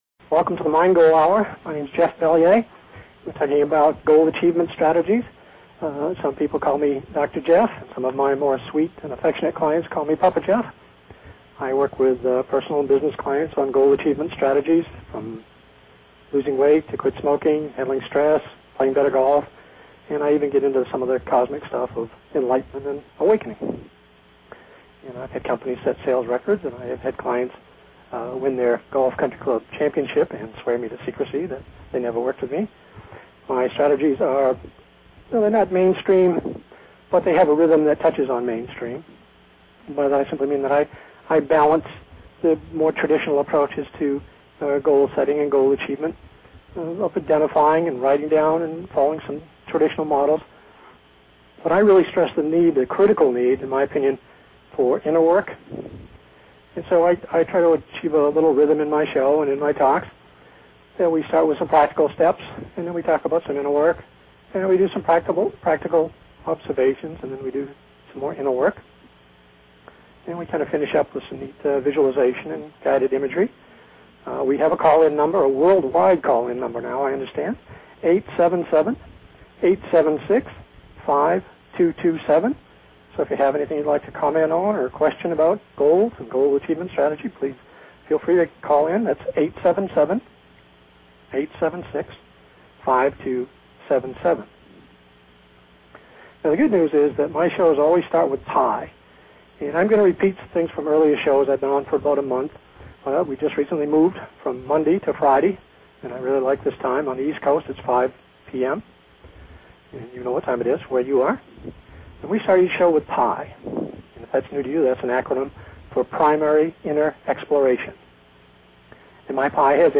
Talk Show Episode, Audio Podcast, Mind_Goal and Courtesy of BBS Radio on , show guests , about , categorized as